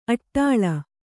♪ aṭṭāḷa